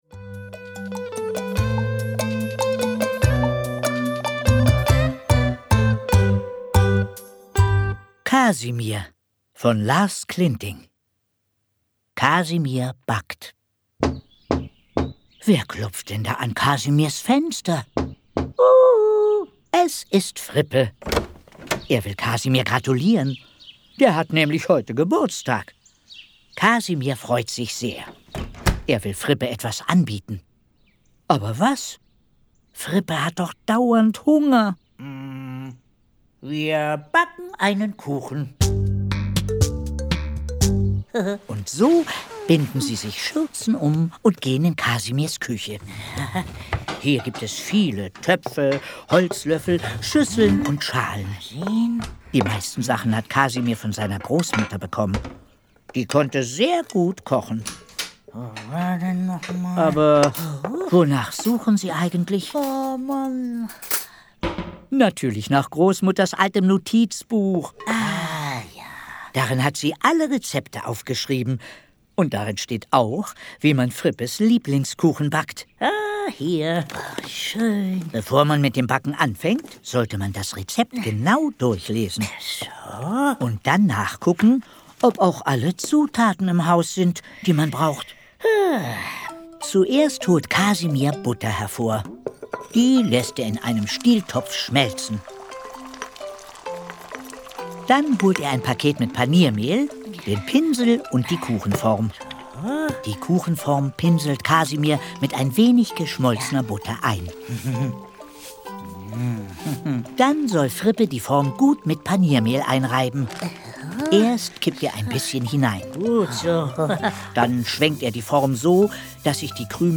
Die schönsten Geschichten von Kasimir Lars Klinting (Autor) Kay Poppe (Komponist) Santiago Ziesmer (Sprecher) Audio-CD 2015 | 5.